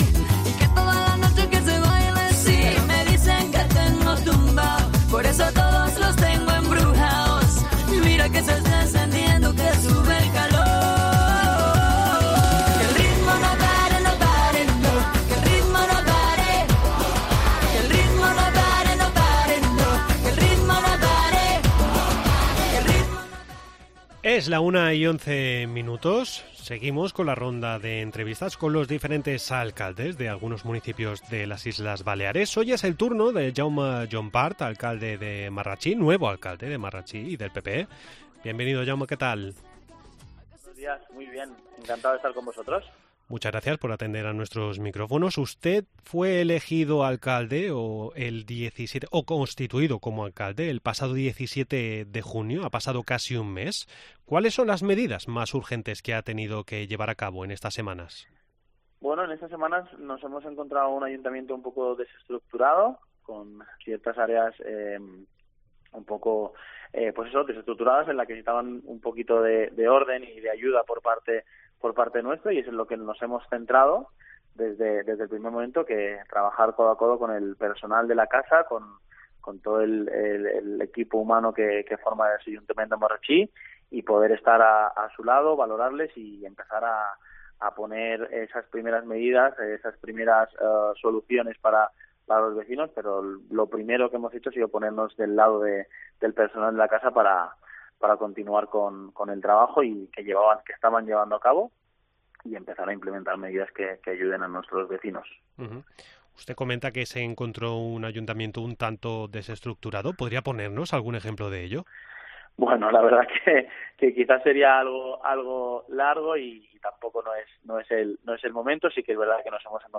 AUDIO: Seguimos hablando con los nuevos alcaldes de los principales municipios de Mallorca.